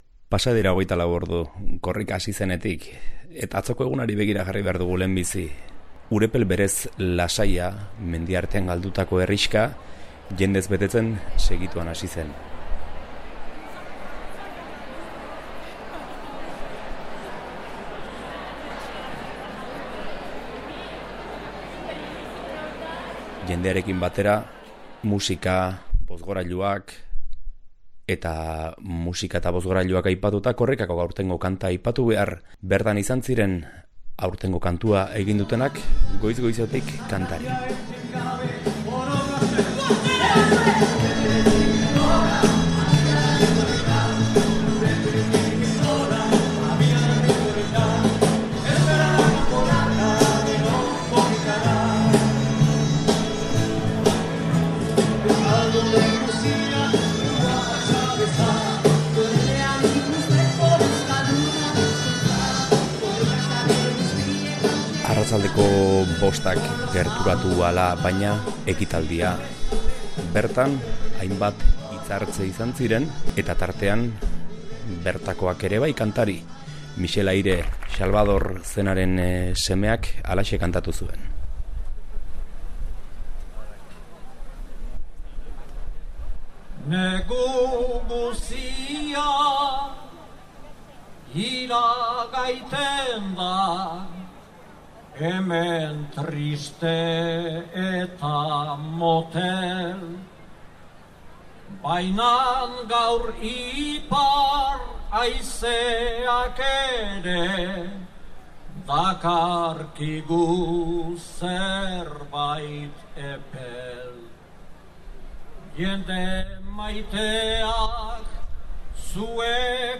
Hemen duzue entzungai lehen egunak emandakoaren kronika: giroko soinua, musika, hitzartzeak, eta nola ez, euskararen normalizaziorako grina.